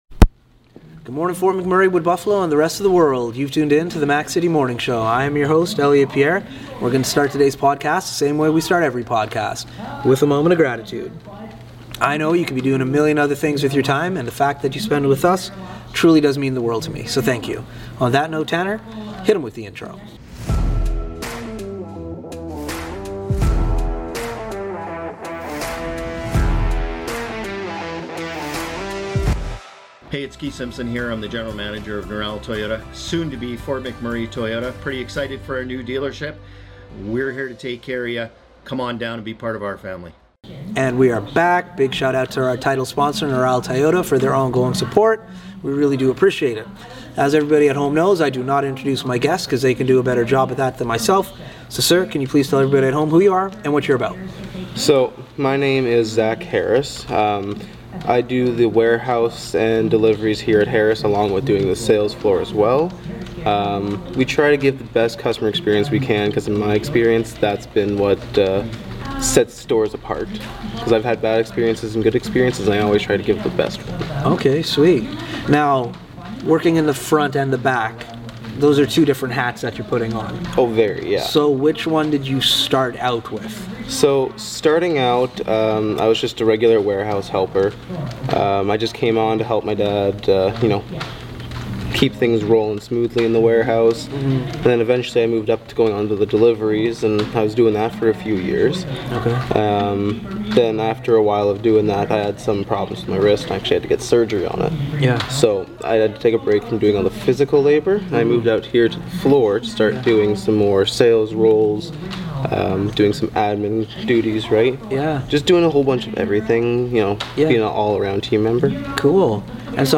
We are on location today